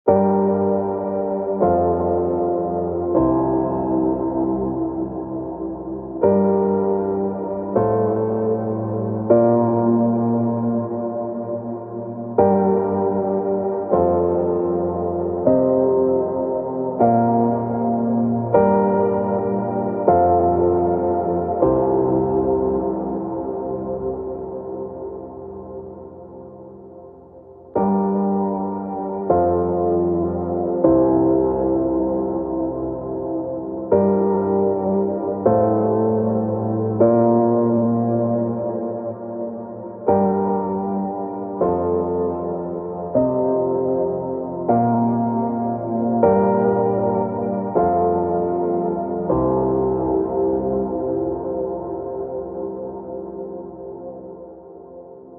Here’s uh a gen loss pre fx (first) and post fx (second) demo and totally not an excuse to post random noodle